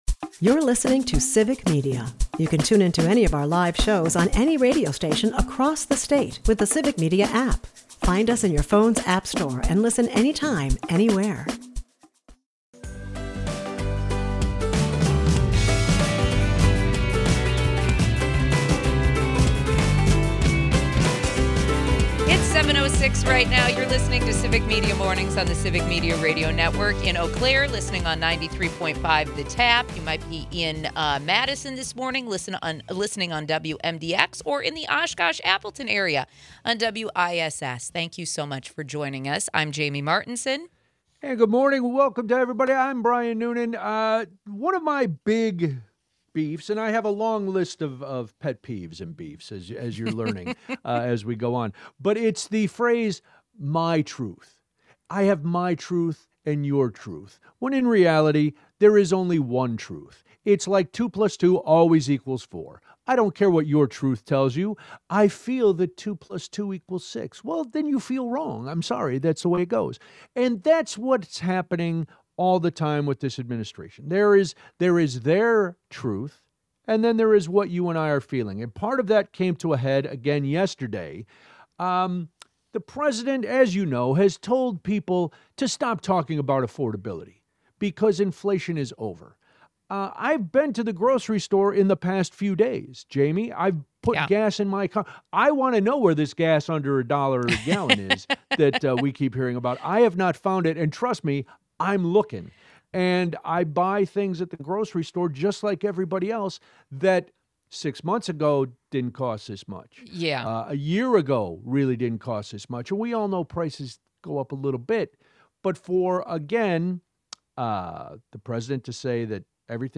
Towards the end of the show, Wisconsin Republicans propose a 'Trump account' to aid children financially. The show takes a lighthearted approach in the last segment by discussing bizarre TSA searches, including turtles hidden in travelers' clothing.